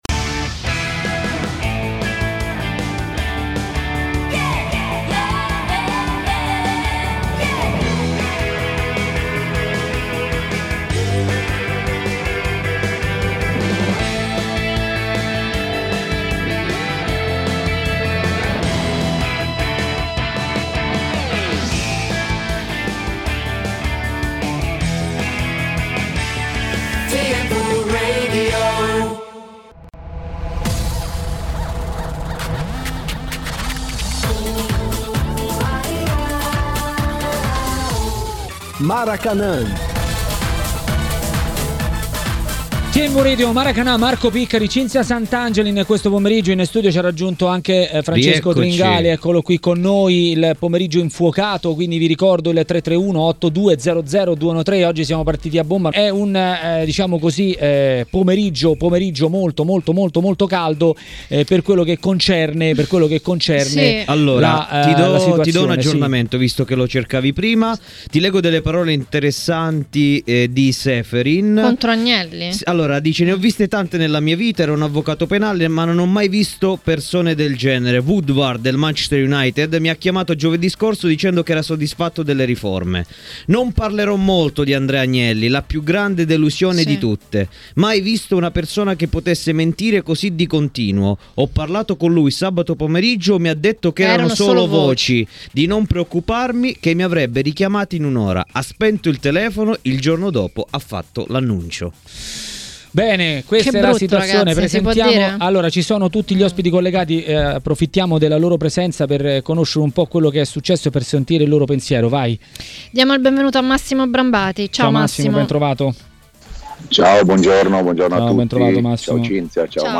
Intervistato da TMW Radio , l'ex calciatore e agente